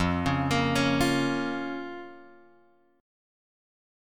F Suspended 4th Sharp 5th